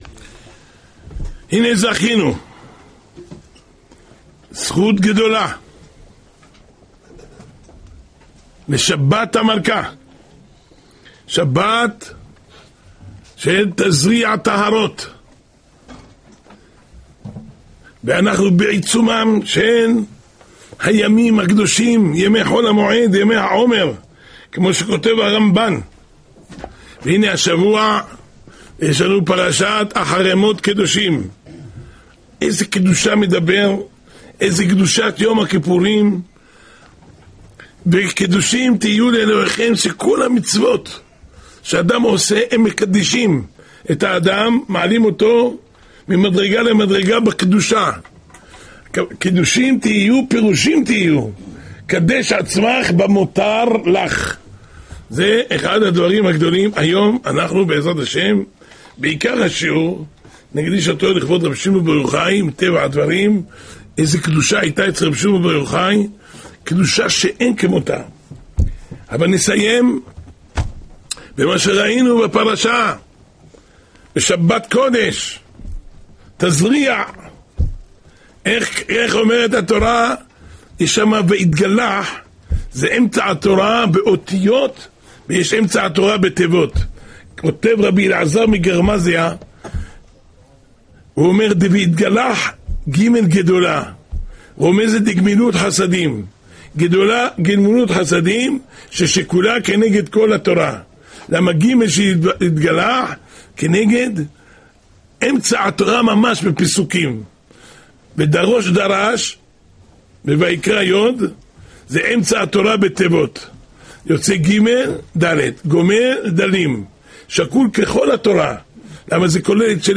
השיעור השבועי